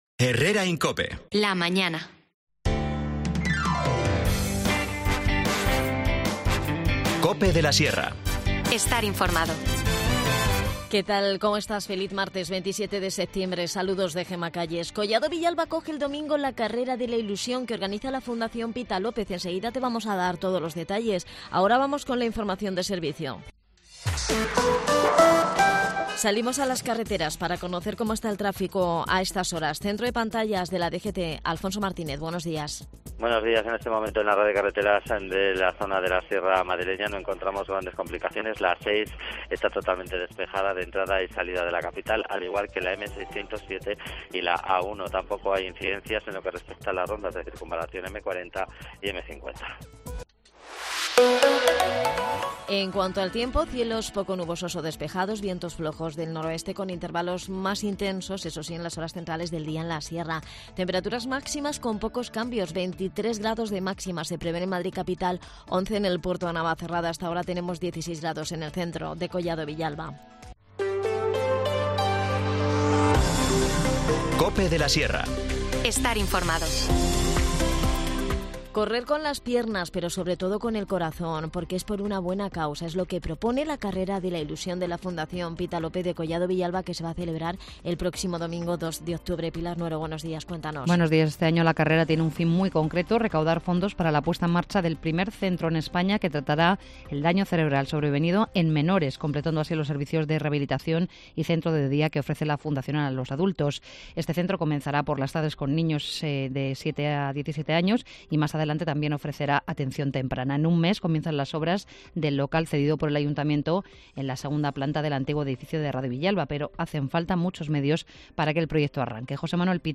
Escucha ya las desconexiones locales de COPE de la Sierra en Herrera en COPE de la Sierra y Mediodía COPE de la Sierra.